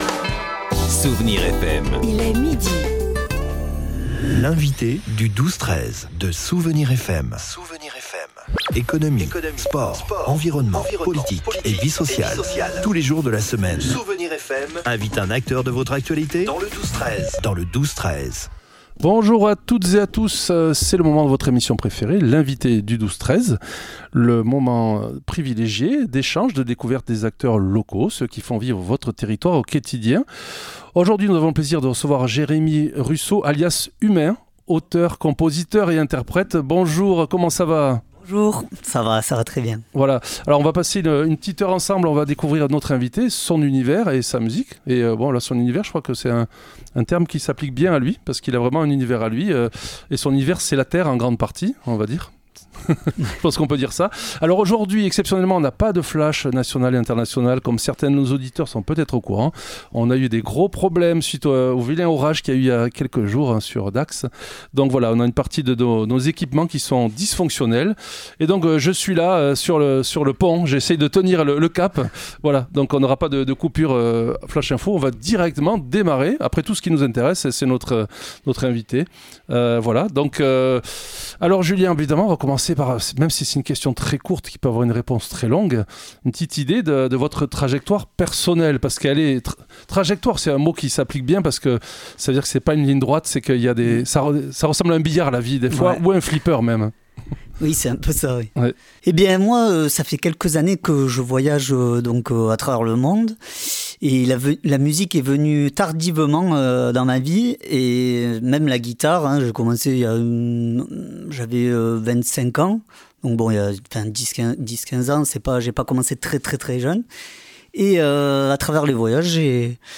Il nous a interprété sa musique et ses paroles, toutes deux imprégnées du monde qu'il a parcouru, avec un fil conducteur : le don.